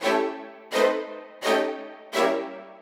GS_Viols_85-A.wav